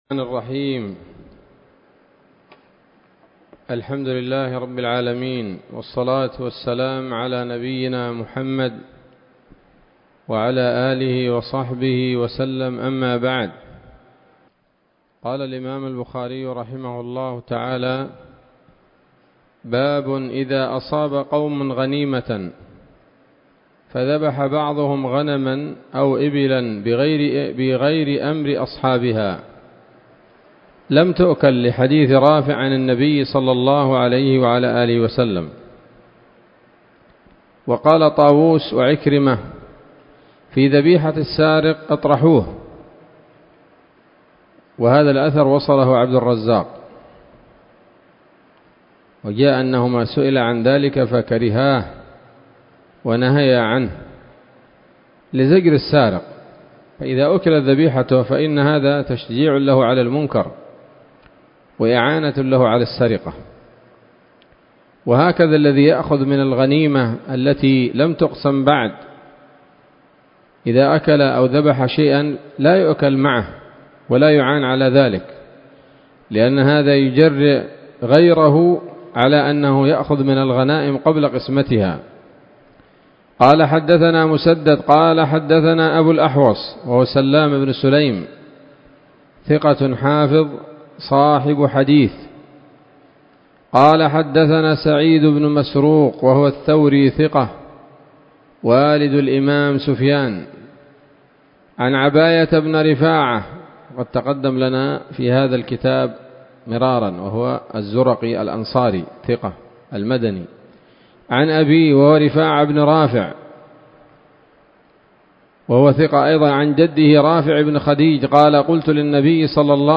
الدرس الحادي والثلاثون وهو الأخير من كتاب الذبائح والصيد من صحيح الإمام البخاري